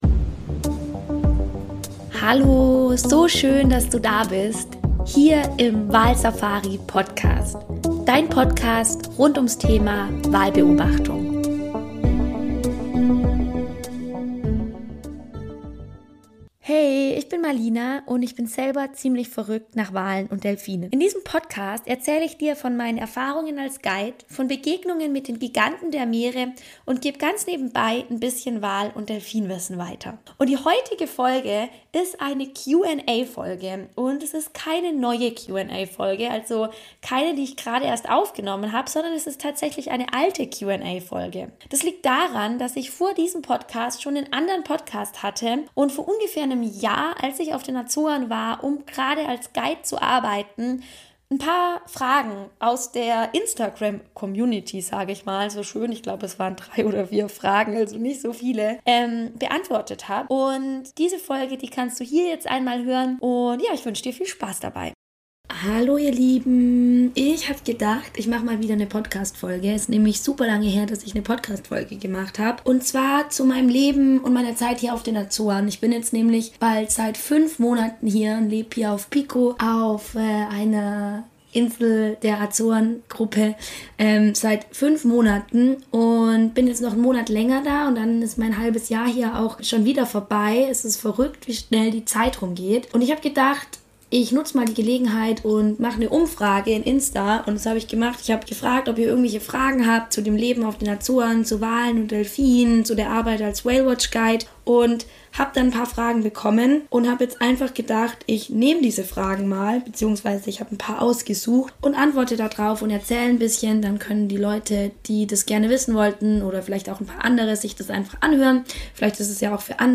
Die heutige Episode ist eine Aufnahme aus dem Jahr 2024, als ich gerade auf Pico auf den Azoren gelebt und als Guide gearbeitet habe – eine echte „Live von den Azoren“-Folge also! Es handelt sich um eine Q&A-Episode, in der ich eure Fragen beantworte, die ihr mir auf Instagram gestellt habt.